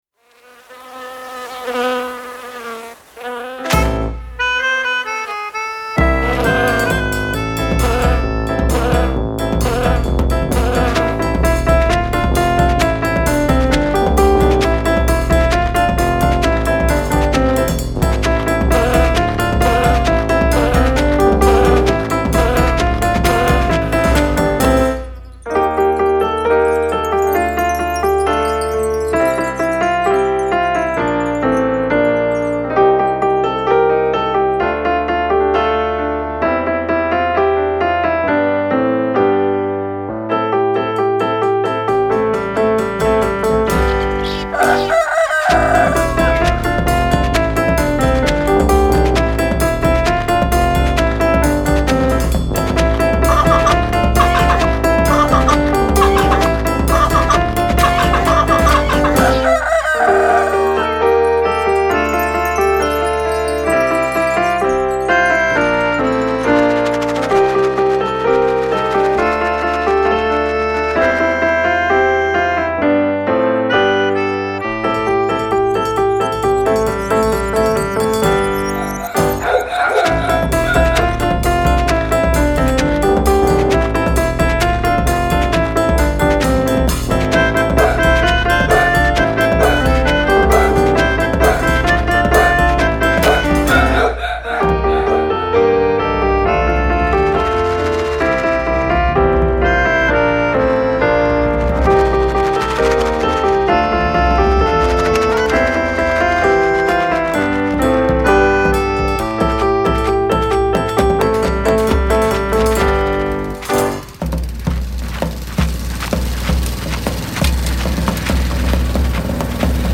Ci-dessous le play-back et la partition.
Instru Il y a du brouhaha dans le potagerTélécharger
Il-y-a-du-brouhaha-INSTRU-dans-le-potager-1.mp3